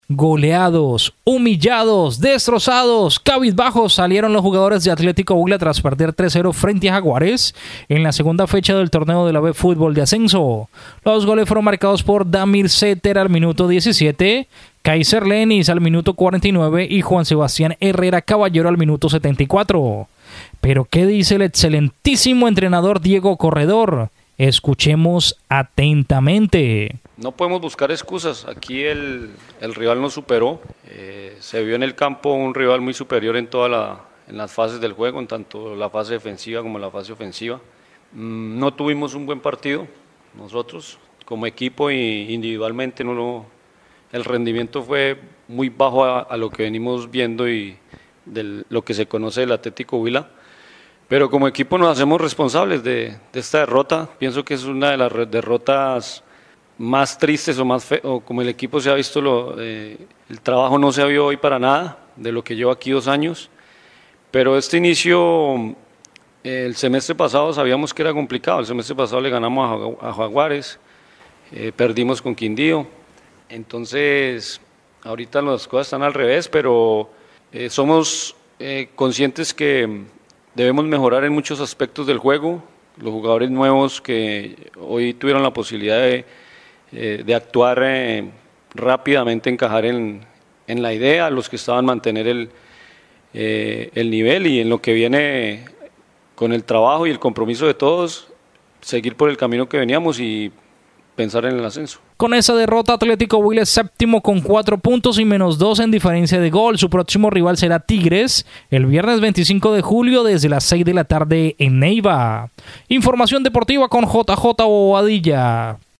VOZ_TITULAR_DEPORTES_21_JULIO.MP3